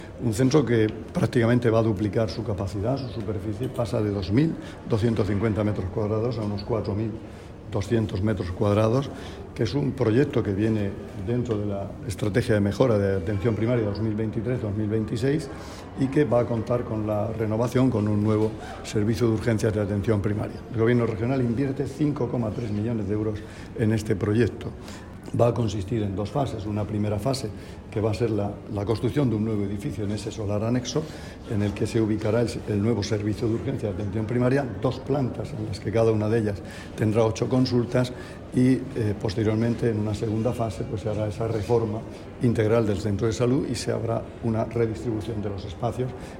Sonido/ Declaraciones del consejero de Salud, Juan José Pedreño, sobre las obras de ampliación del centro de salud de Archena